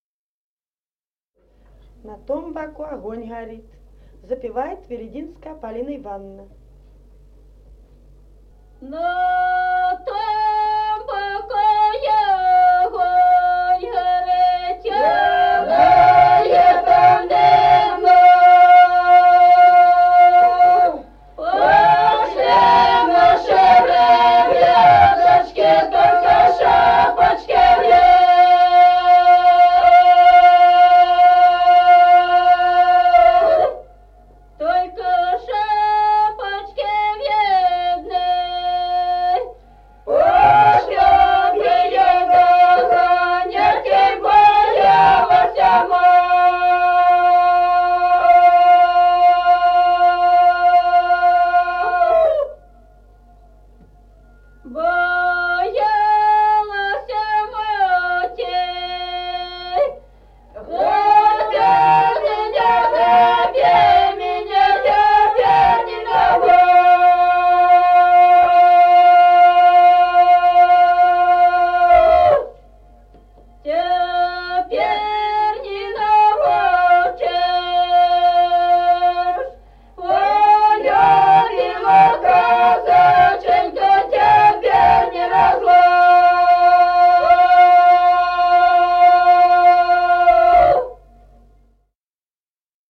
Народные песни Стародубского района «На том боку огонь горит», петровская.
с. Остроглядово.